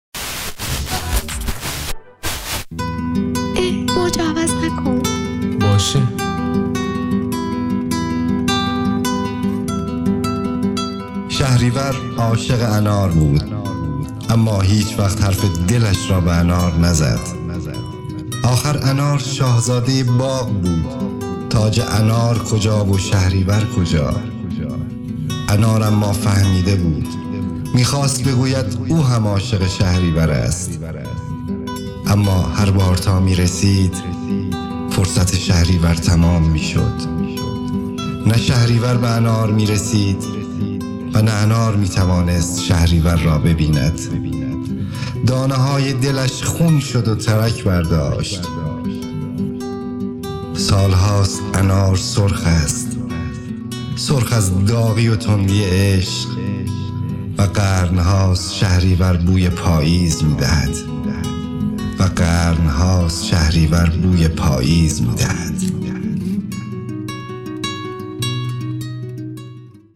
میکس و مسترینگ